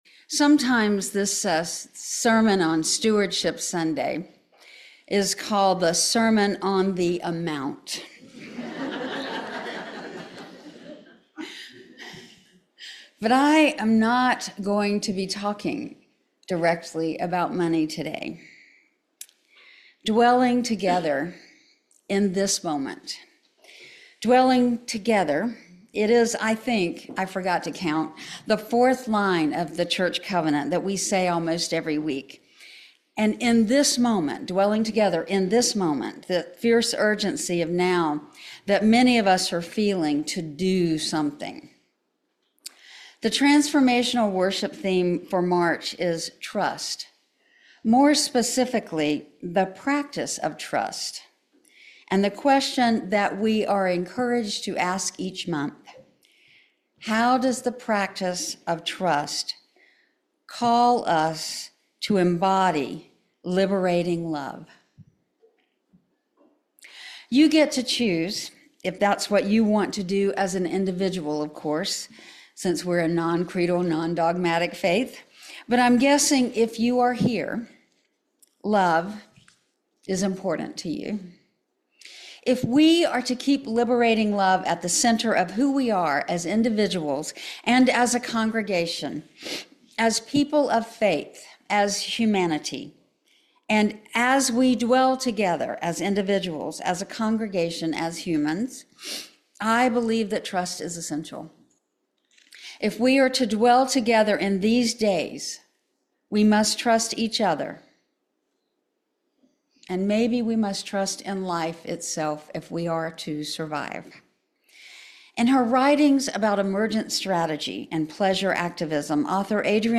This sermon emphasizes that trust is the essential foundation for building a resilient and liberating spiritual community.